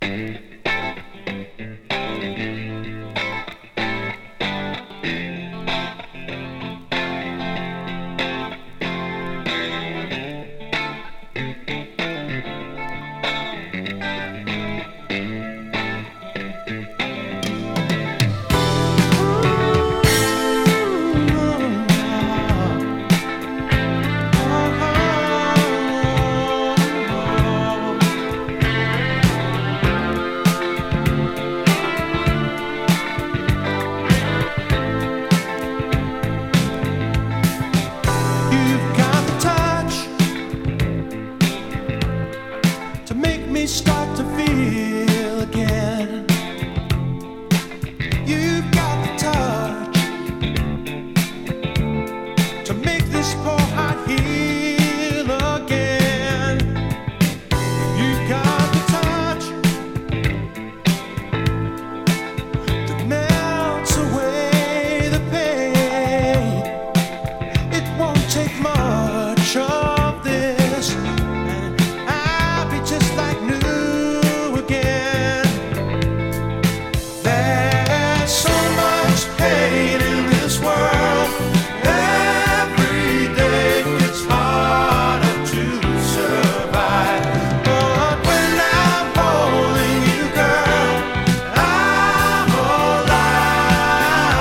ポップなロックサウンドの中にメロウAORが隠れてました！